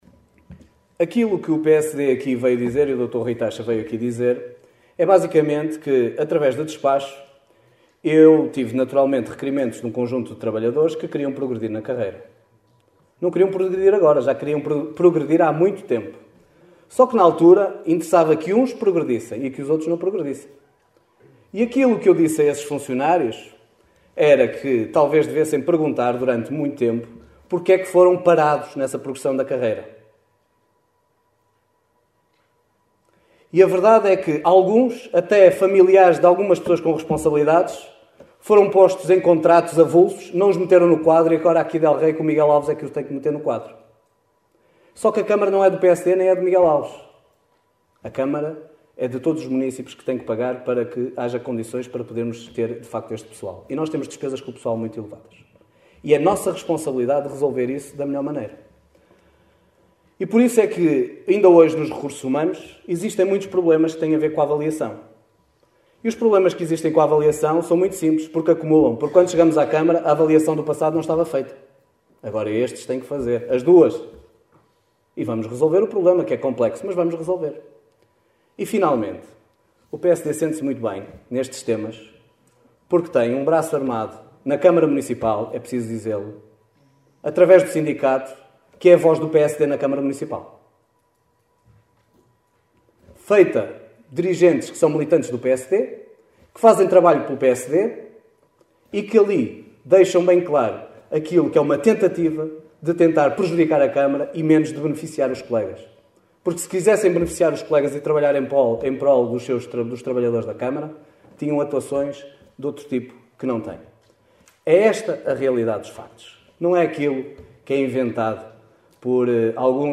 Em resposta à intervenção de Rui Taxa, o líder do executivo socialista, Miguel Alves, lamentou que o PSD tenha “um braço de ferro armado com a Câmara através do Sindicato” que considerou ser a voz dos sociais-democratas na autarquia